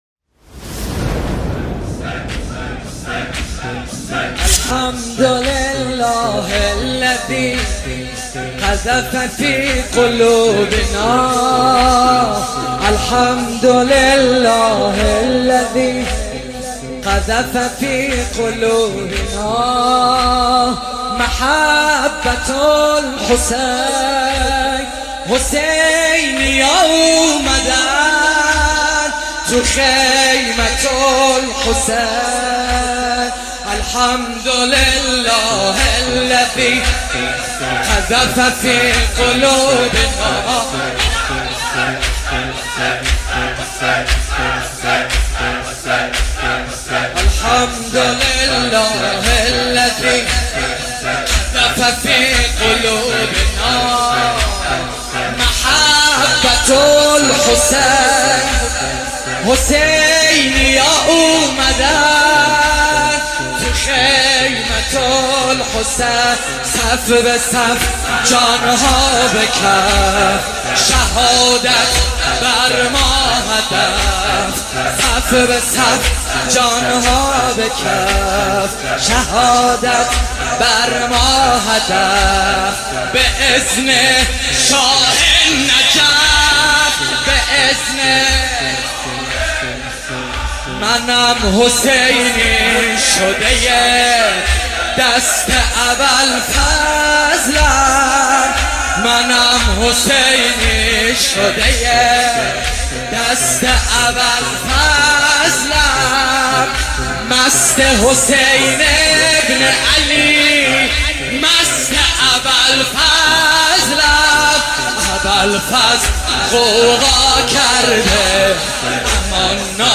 زمینه | صف به صف جان‌ها به کف شهادت بر ما هدف
مداحی
در شب دهم محرم (شب عاشورا)